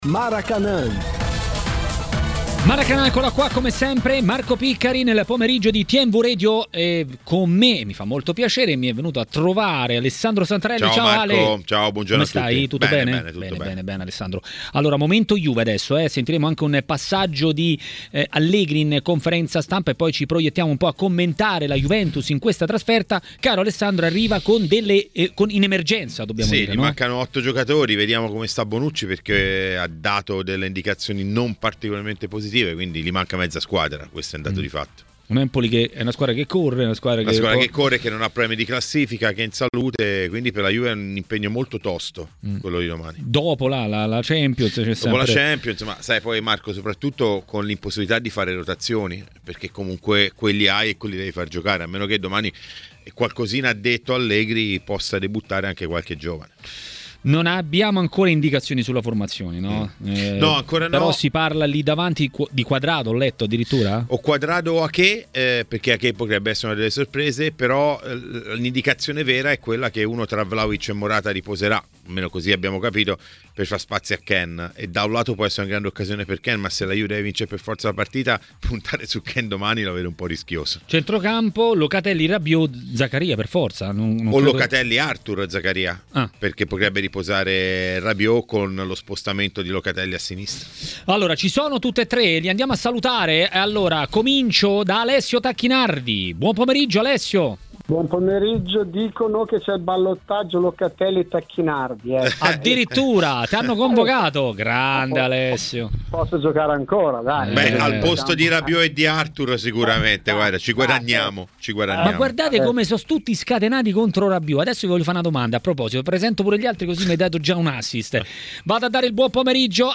A TMW Radio, durante Maracanà, è arrivato il momento del tecnico ed ex calciatore Alessio Tacchinardi.